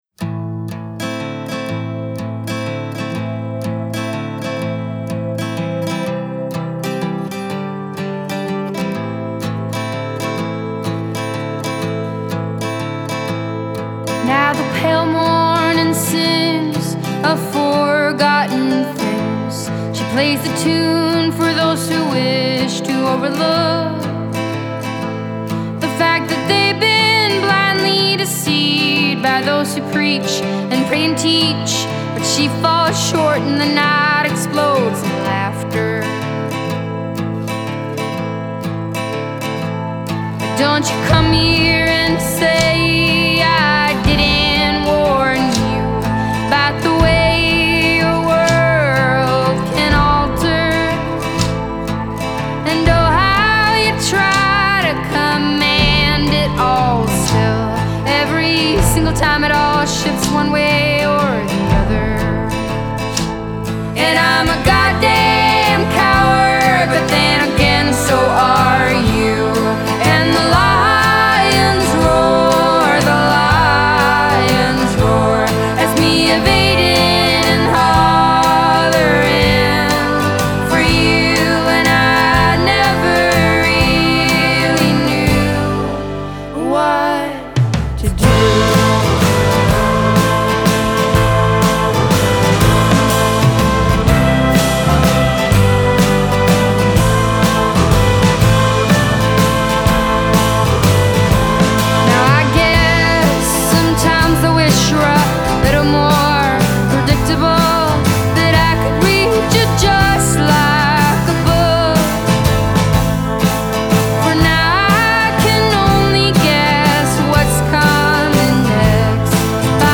Играют лёгкий акустический инди фолк-рок.
Genre: Folk / Indie / Sweden